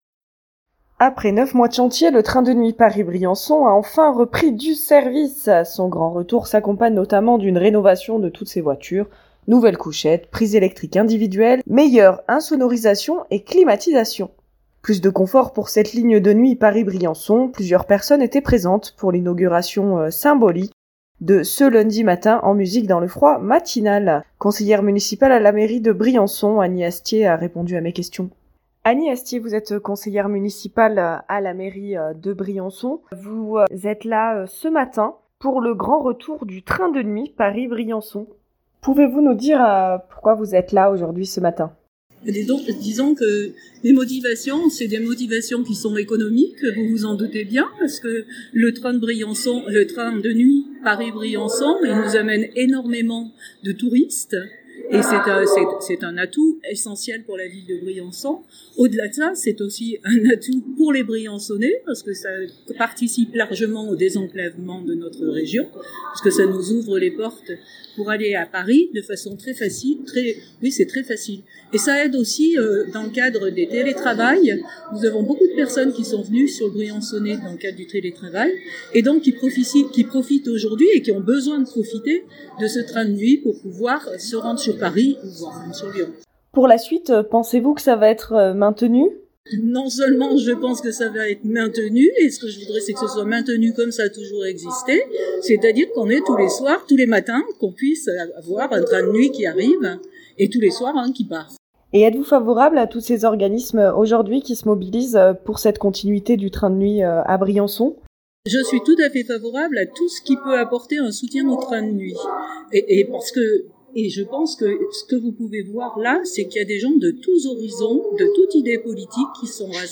Plusieurs personnes étaient présentent pour l'inauguration symbolique qui s'est déroulée ce lundi matin en musique dans le froid matinal.